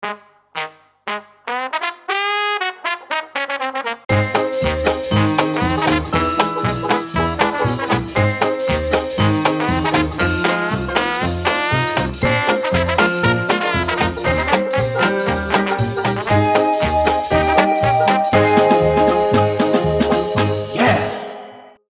Single patch demo